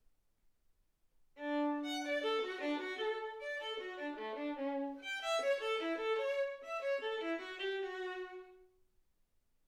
Hegedű etűdök Kategóriák Klasszikus zene Felvétel hossza 00:10 Felvétel dátuma 2025. december 8.